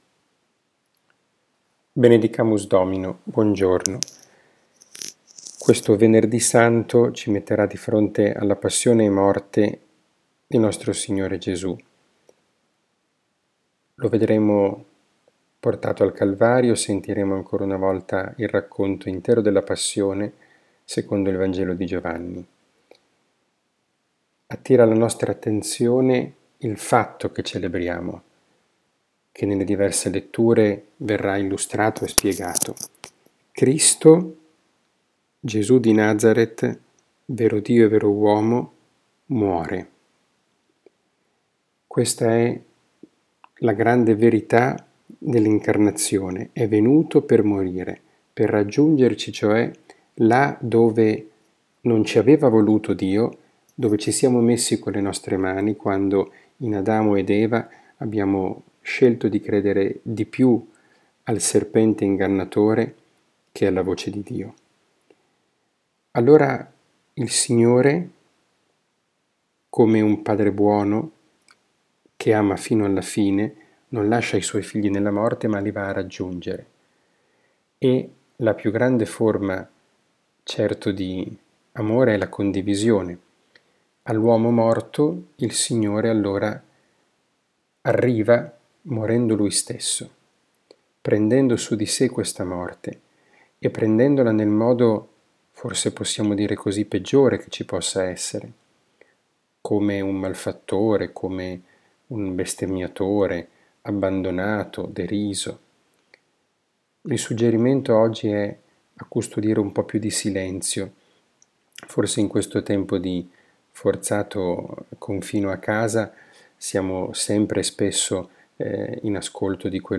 catechesi, Parola di Dio, podcast